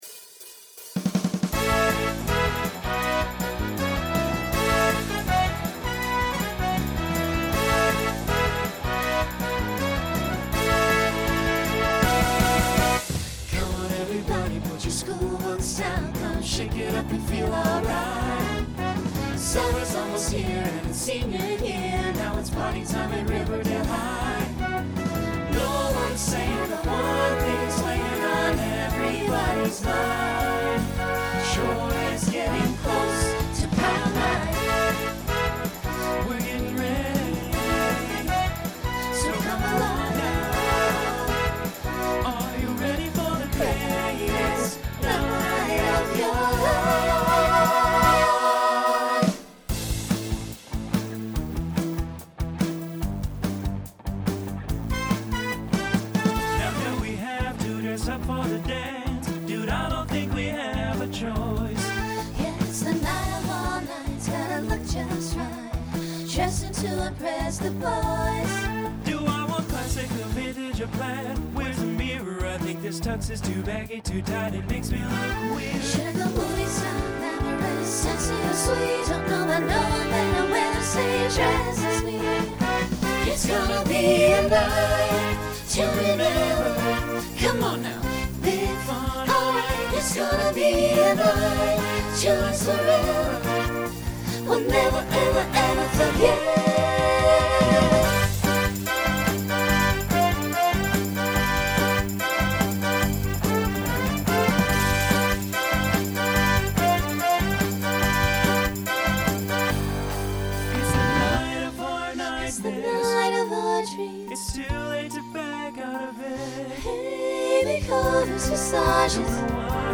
Broadway/Film Instrumental combo
Voicing SAB